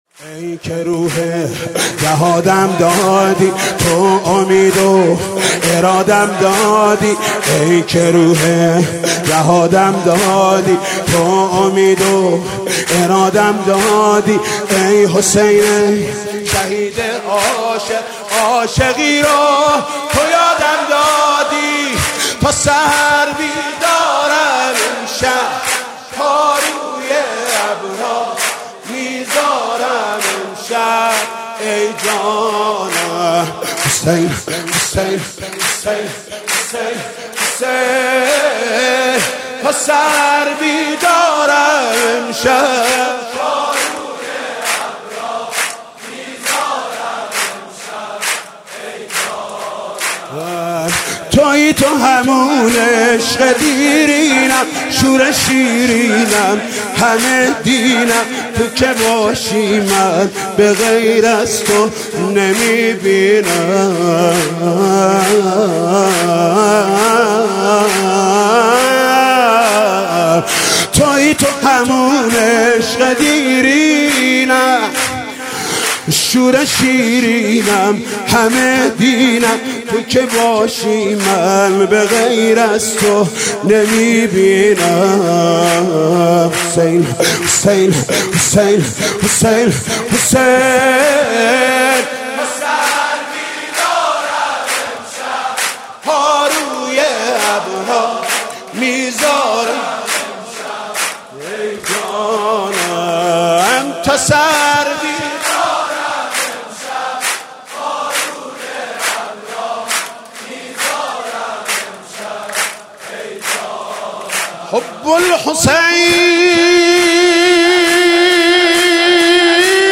سرود: ای که روح جهادم دادی